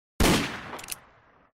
gun